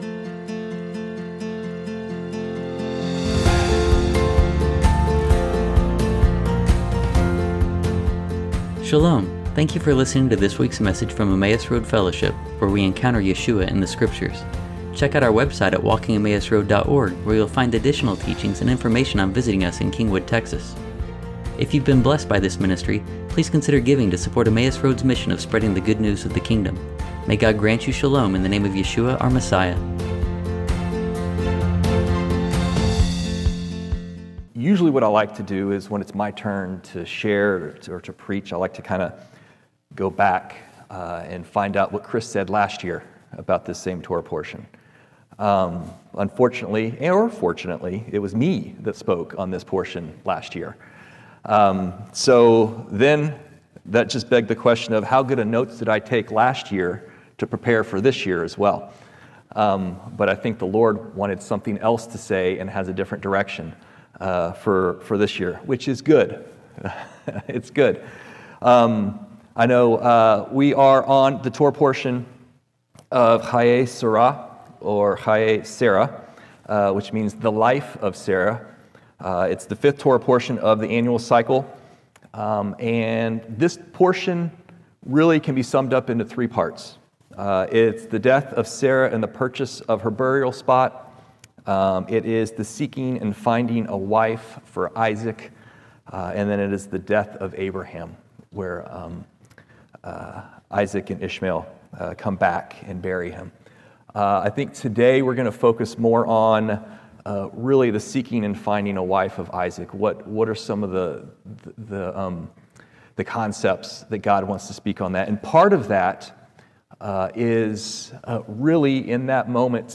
The sermon encourages us to build emotional, social, and spiritual legacies that honor God and benefit future generations.